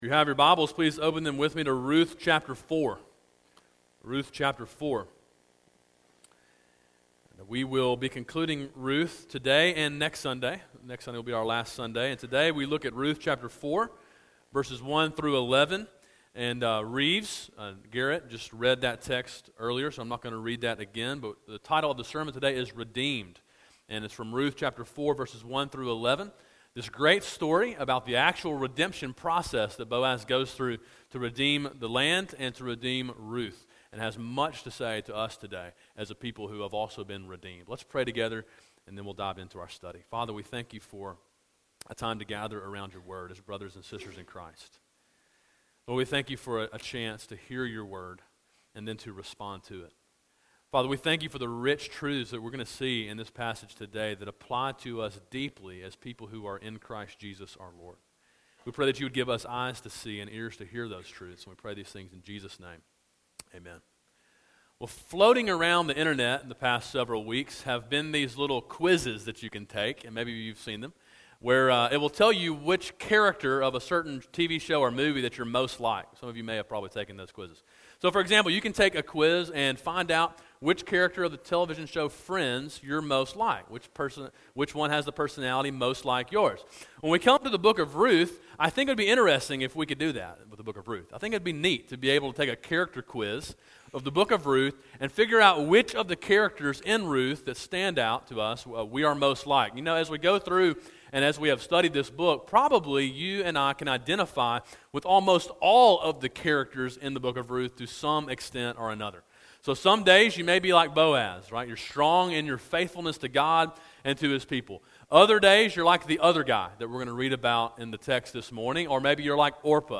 A sermon in a series on the book of Ruth.